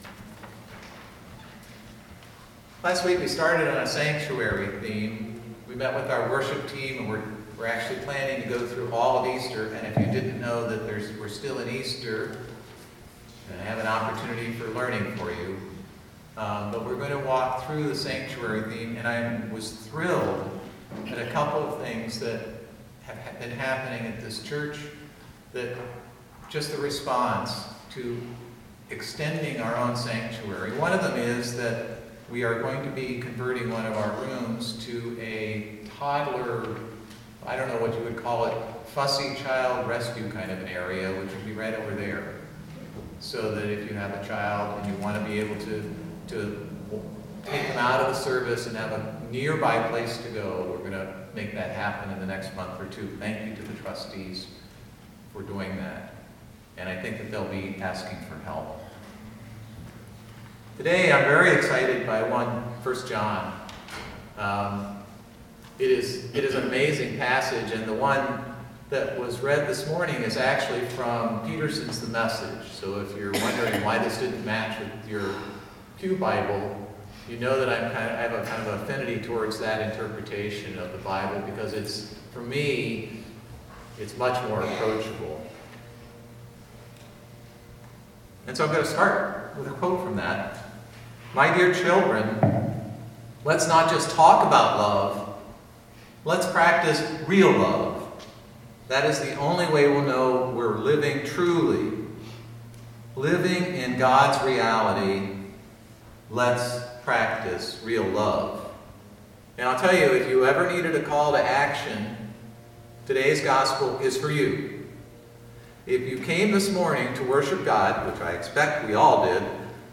Sermon from April 22, 2018